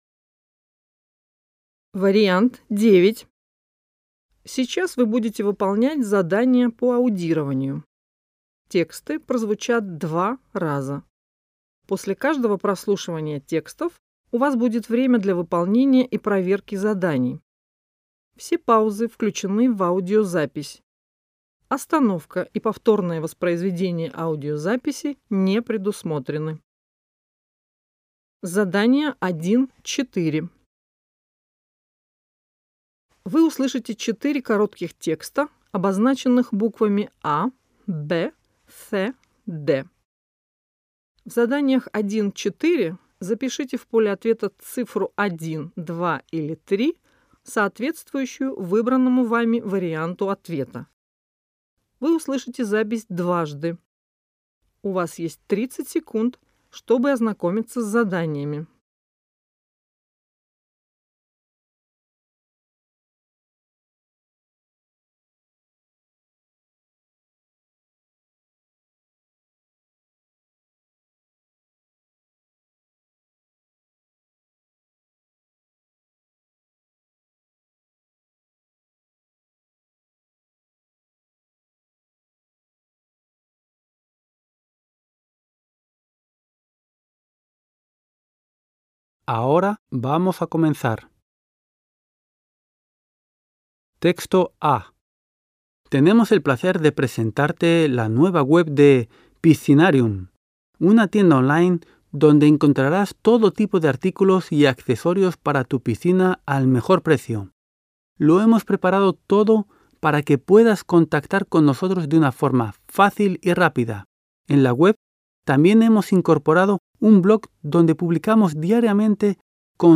В разделе 1 (задания по аудированию) предлагается прослушать несколько текстов и выполнить 11 заданий на понимание прослушанных текстов.